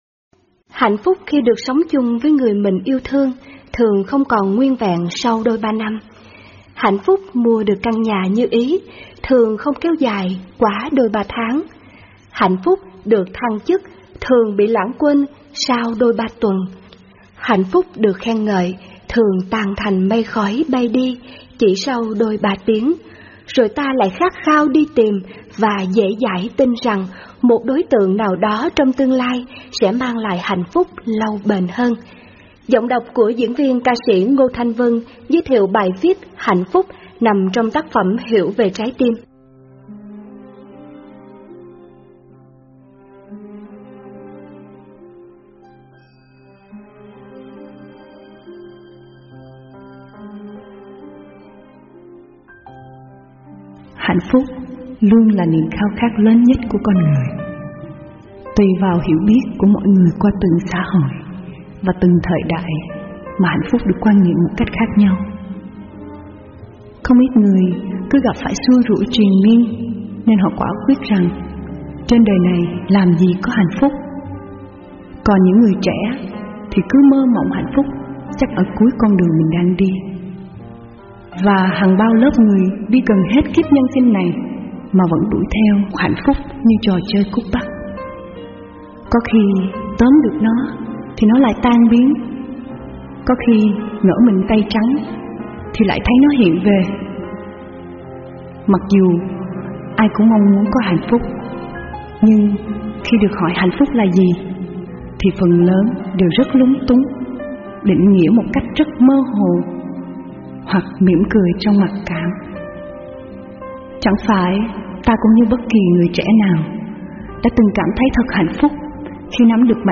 Hiểu về trái tim Sách nói mp3